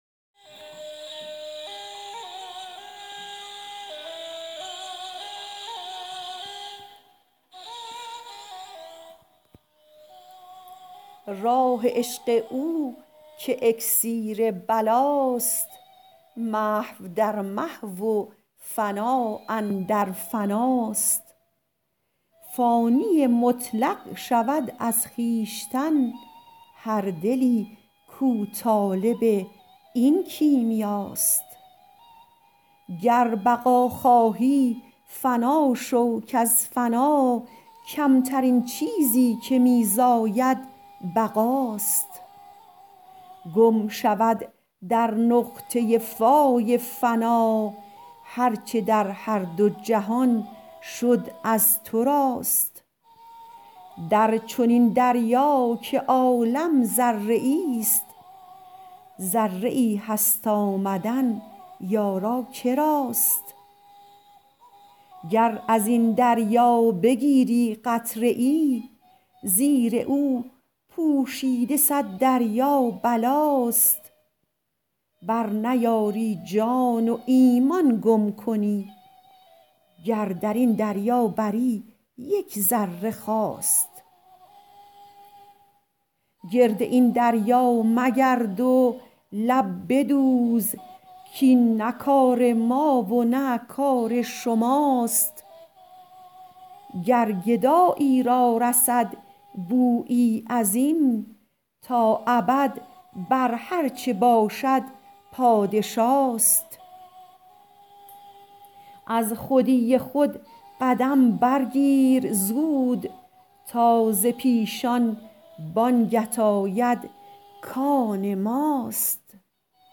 غزل شمارهٔ ۳۴ به خوانش